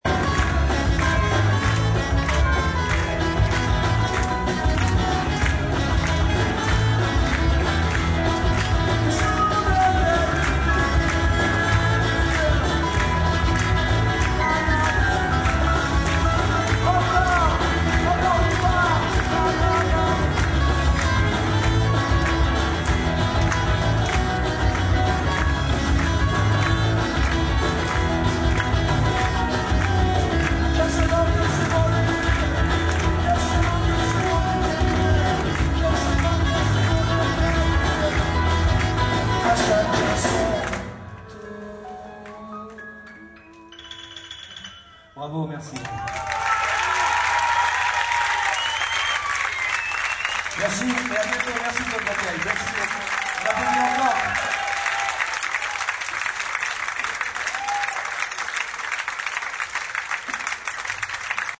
enregistré en public le 11 mars 2001 au théatre Mondory